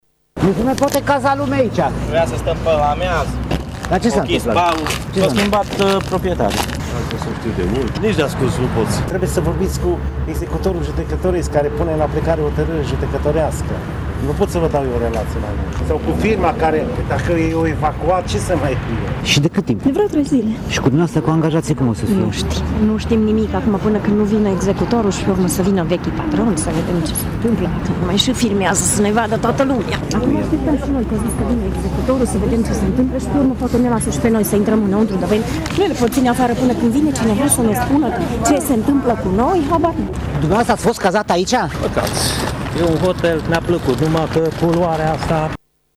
Oaspeții și angajații hotelului, dar și cei care urmau să petreacă Revelionul în această locație s-au declarat dezamăgiți de măsura luată: